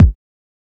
DJP_KICK_ (39).wav